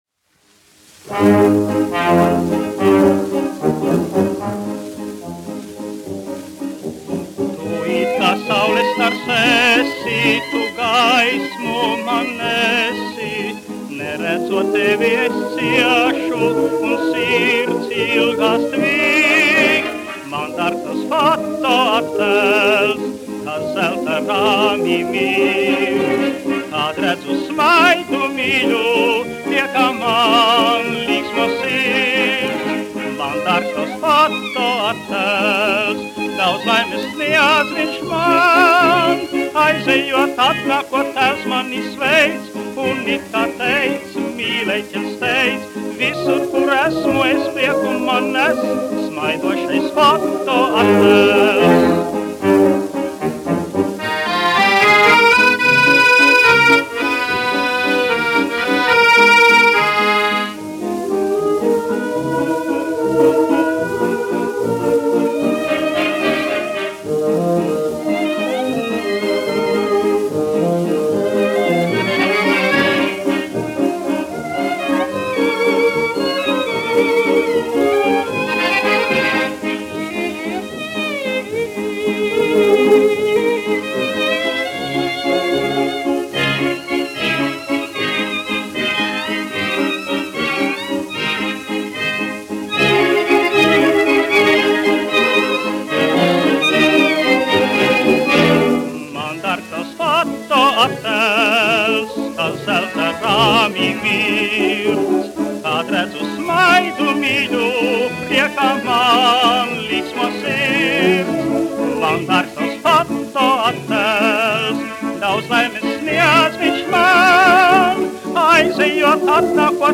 1 skpl. : analogs, 78 apgr/min, mono ; 25 cm
Populārā mūzika
Latvijas vēsturiskie šellaka skaņuplašu ieraksti (Kolekcija)